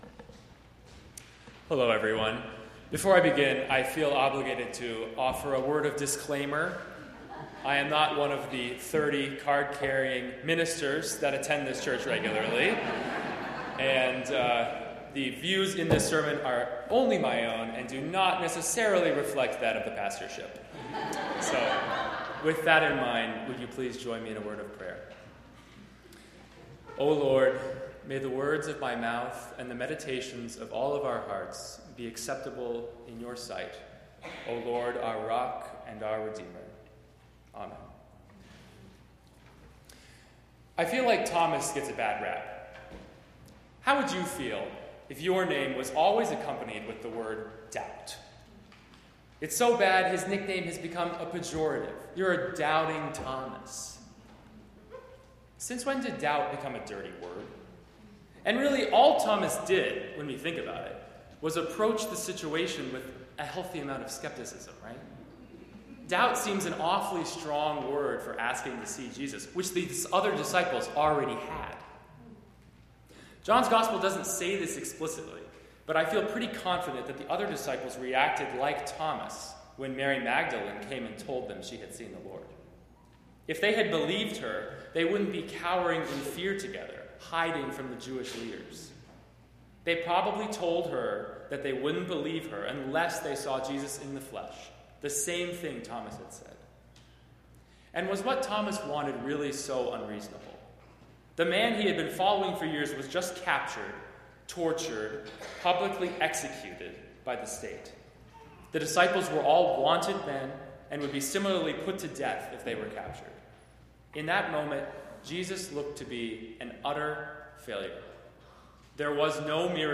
4-28-19-sermon.mp3